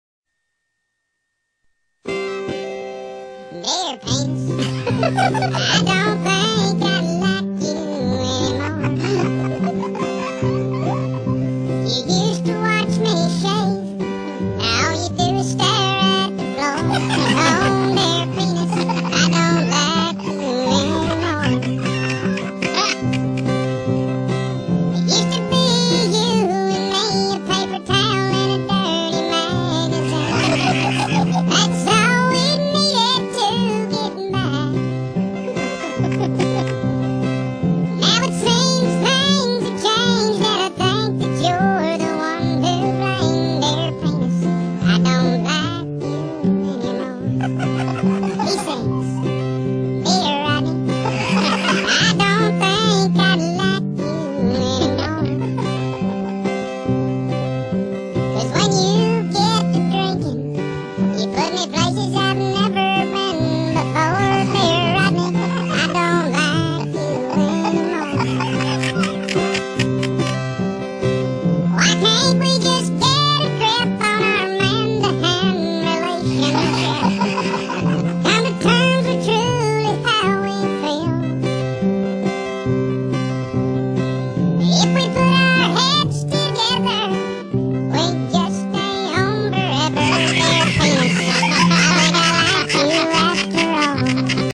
1960's folk